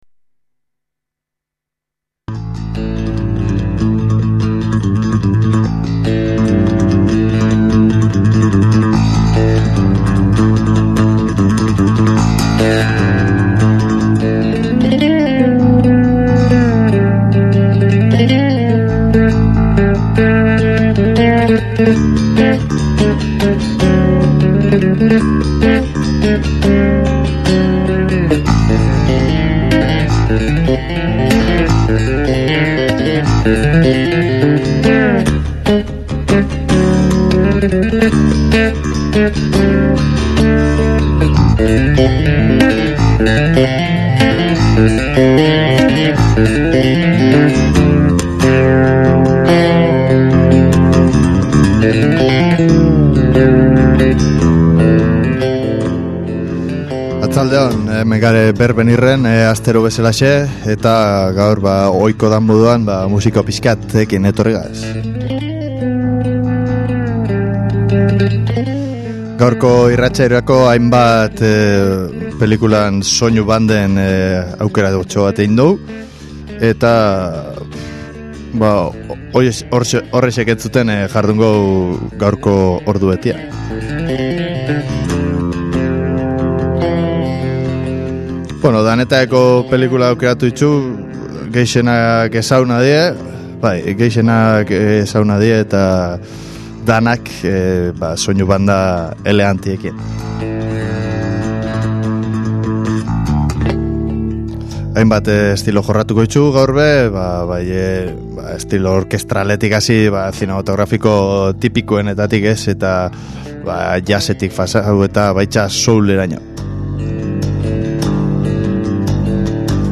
Zaharrenak, berrienak, azkarrak, geldoak, alaiak, tristeak, ezagunak edo ezezagunak.